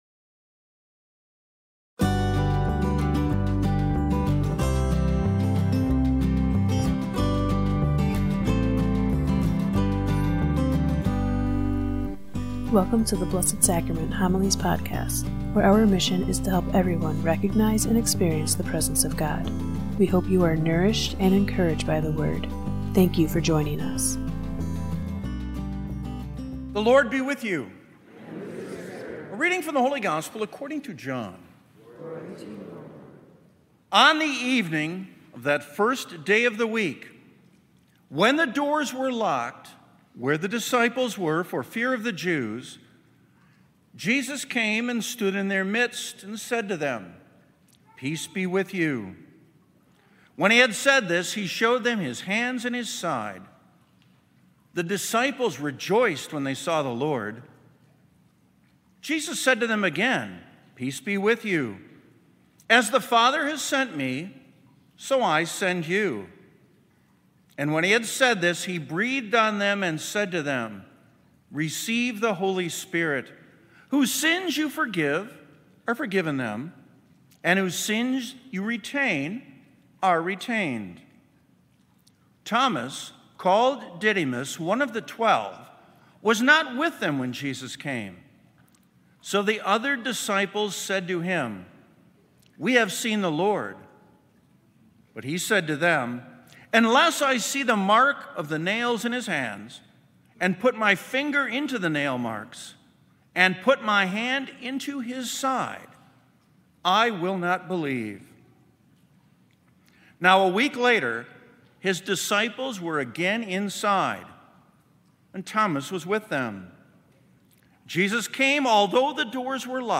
Blessed Sacrament Parish Community Homilies
homily-416.mp3